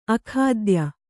♪ akhādya